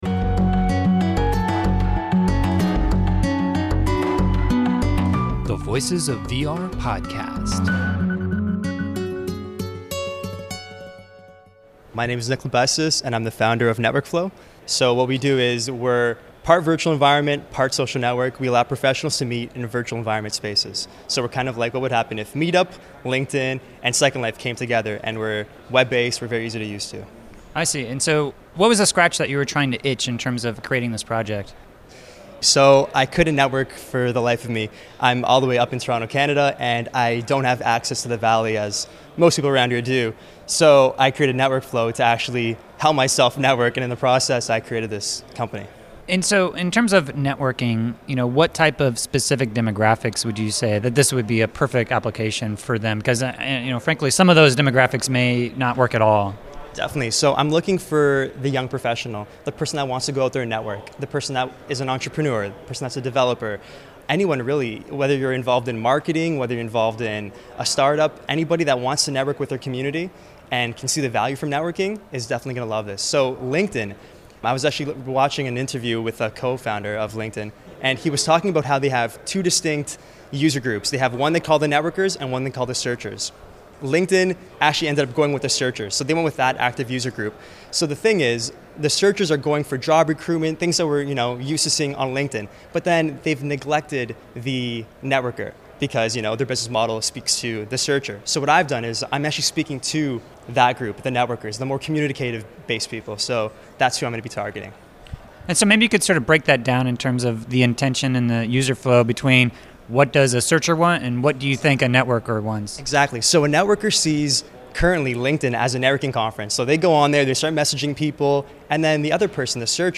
Theme music: “Fatality” by Tigoolio